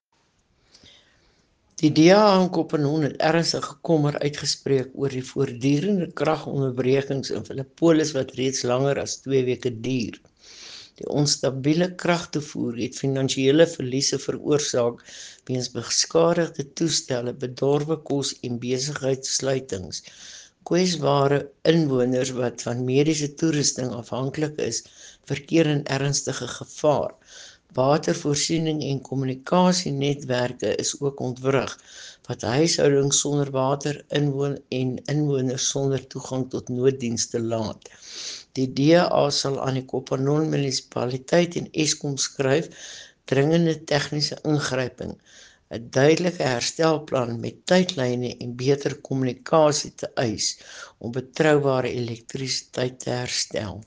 Afrikaans soundbites by Cllr Estelle Noordman and